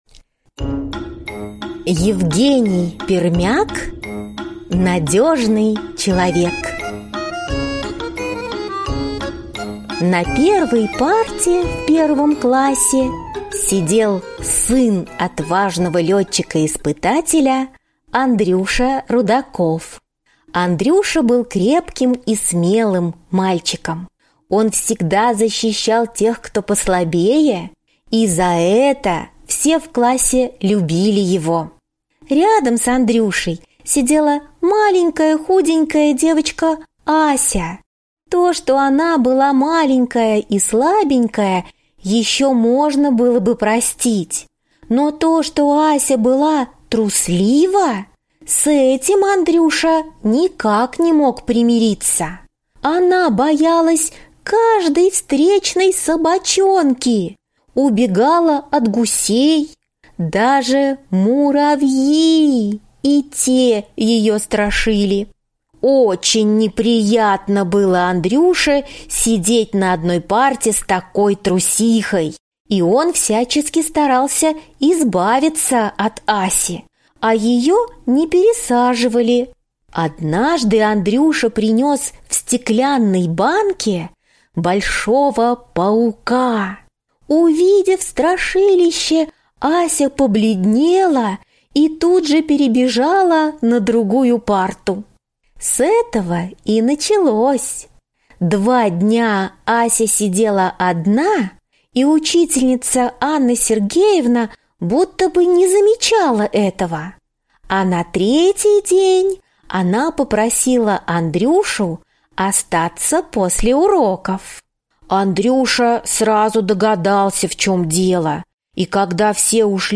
Аудиорассказ «Надежный человек»